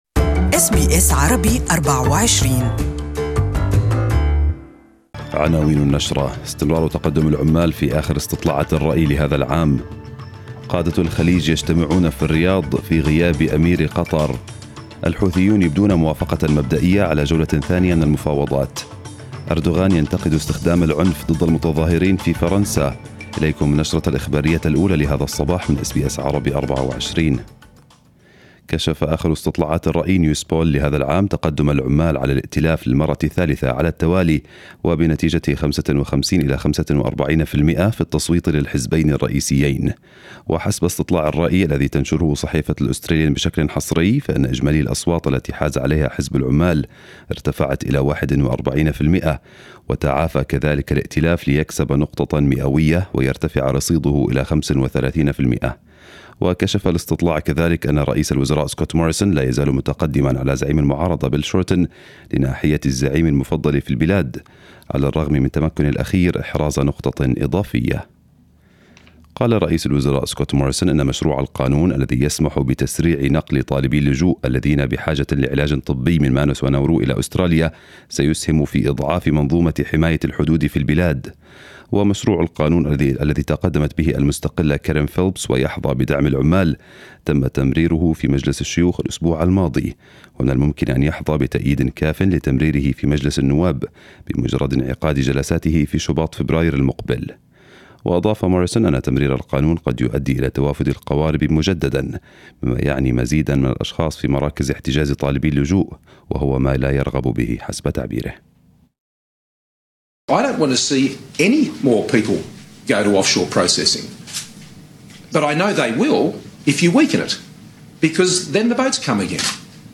نشرة الأخبار المفصلة لهذا الصباح